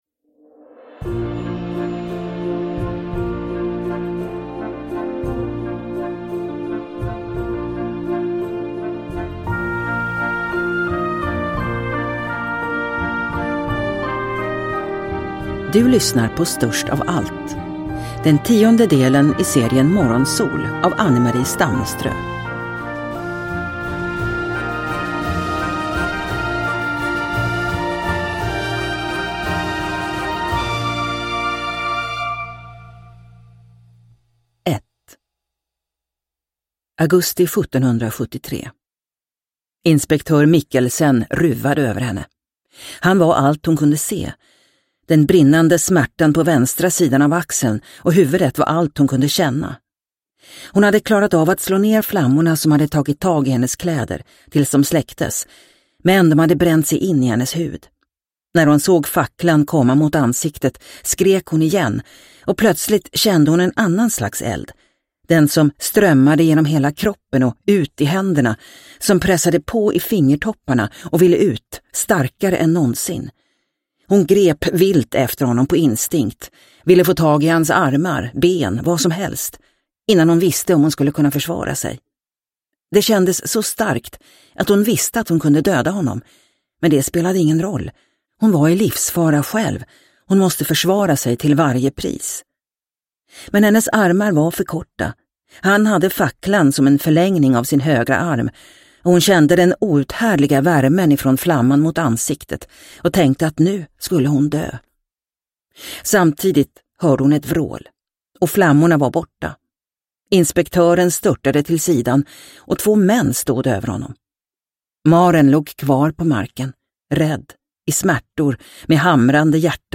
Störst av allt – Ljudbok – Laddas ner